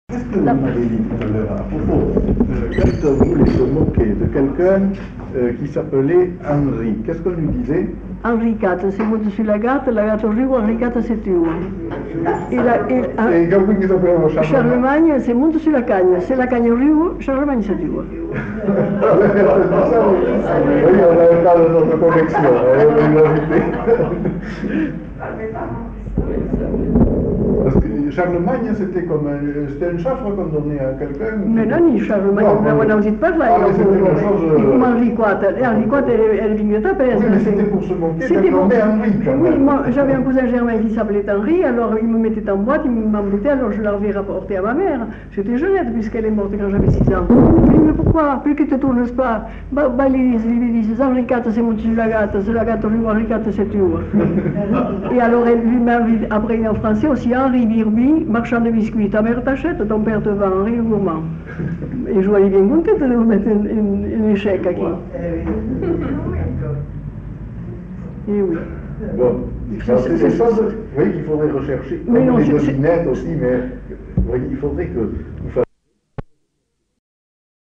Aire culturelle : Bazadais
Lieu : Bazas
Genre : forme brève
Type de voix : voix de femme
Production du son : récité